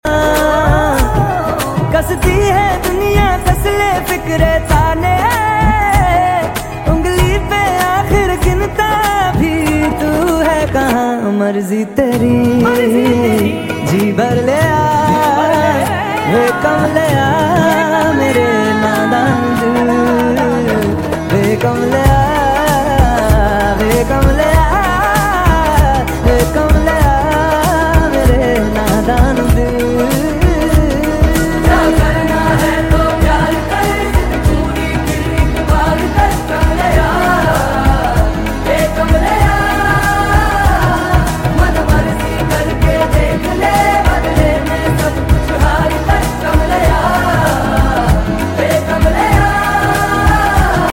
Salman Ali agha Prees conference sound effects free download